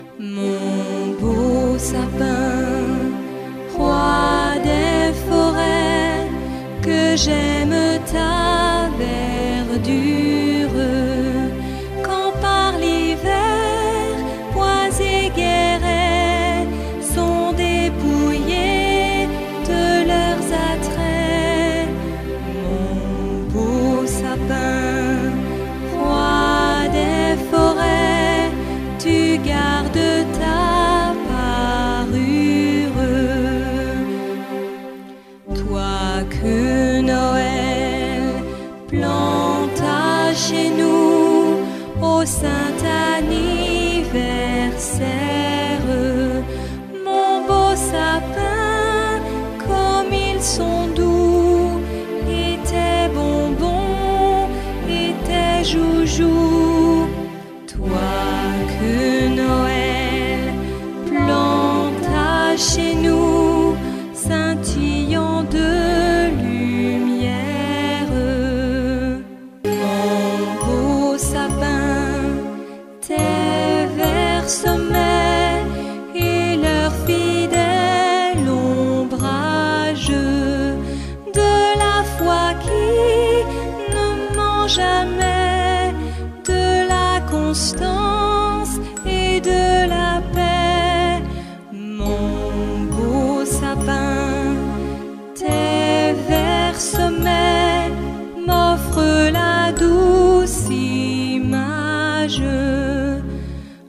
Übungsmusik aus einigen YouTube Videos herausgeschnitten (mittlere Qualität) - als mp3- oder wav-File